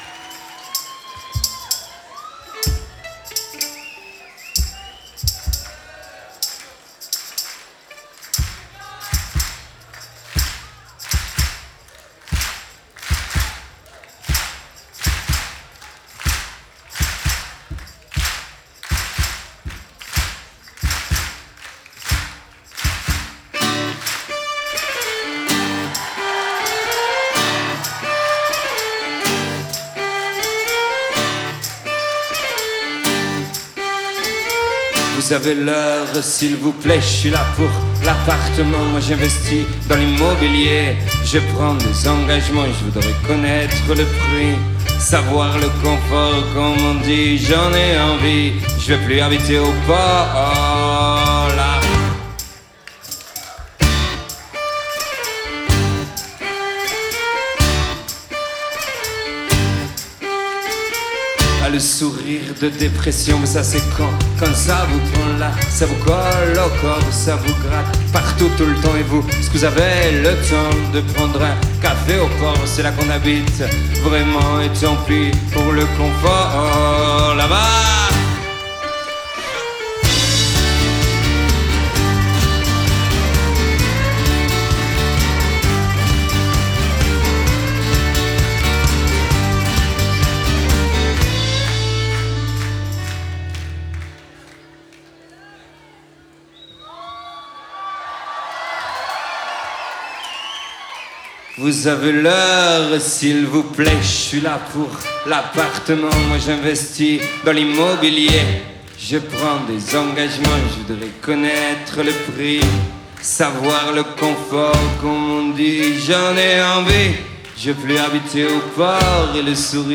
Live à la salle de la Cité, Rennes